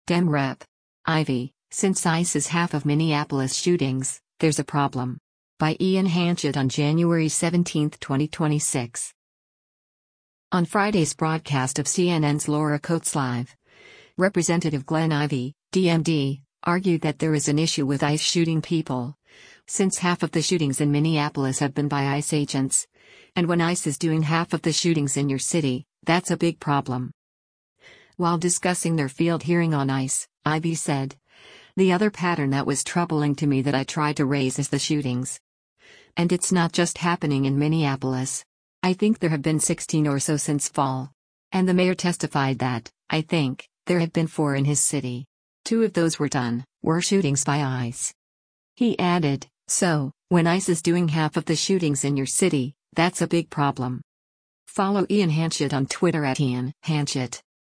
On Friday’s broadcast of CNN’s “Laura Coates Live,” Rep. Glenn Ivey (D-MD) argued that there is an issue with ICE shooting people, since half of the shootings in Minneapolis have been by ICE agents, and “when ICE is doing half of the shootings in your city, that’s a big problem.”